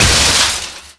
pd_metal1.wav